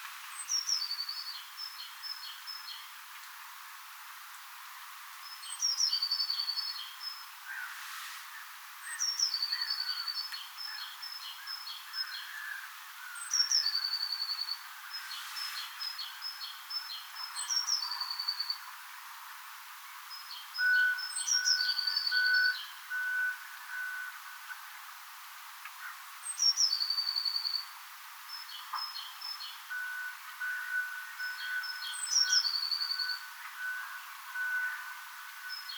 sinitiainen laulaa
sinitiainen_laulaa.mp3